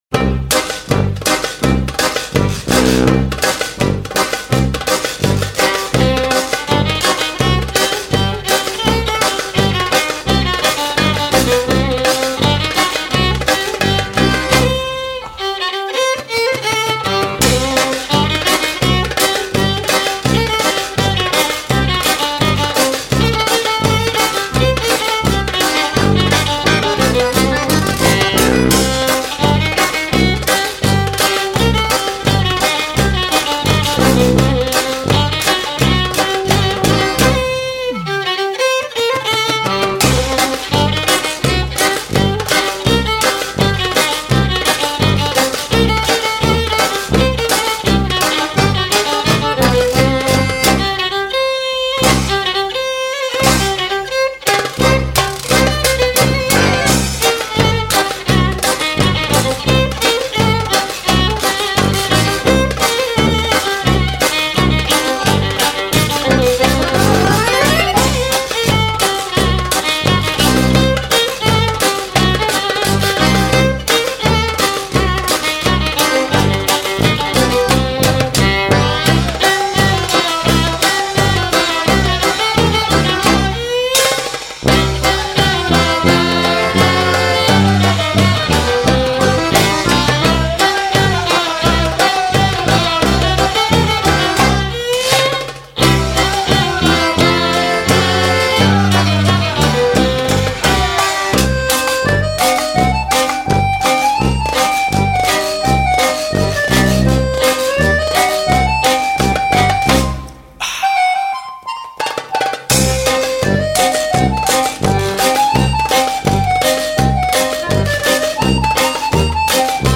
цимбалы, большой барабан, стиральная доска, вокал
туба